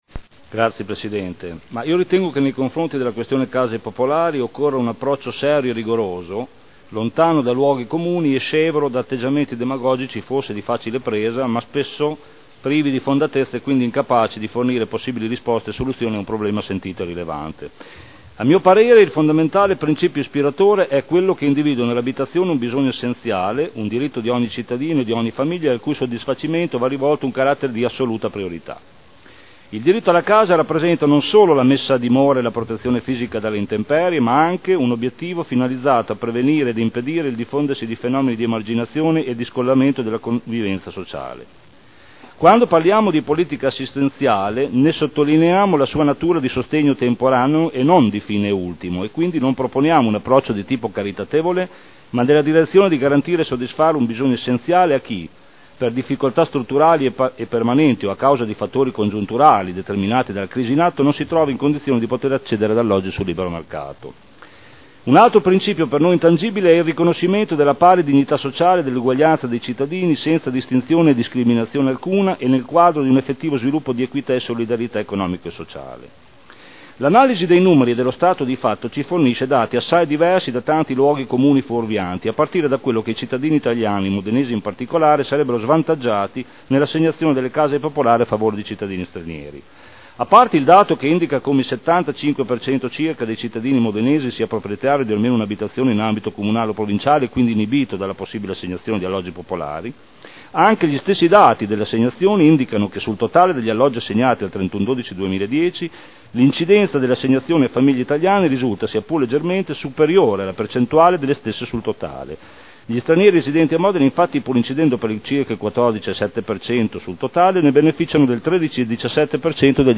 Seduta del 27/06/2011. Discussione sugli ordini del giorno presentati in consiglio comunale inerenti la modifica dei criteri di assegnazione delle case popolari